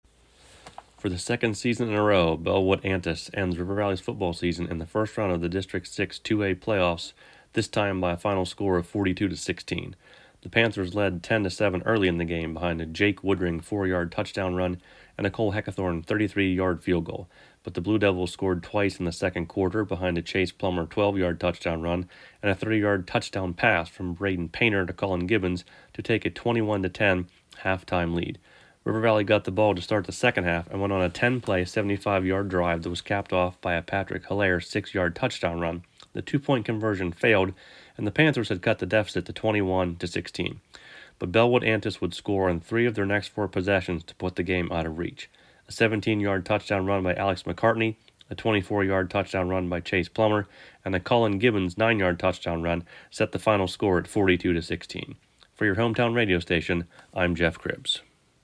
had the recap on Cat Country and Renda Digital TV.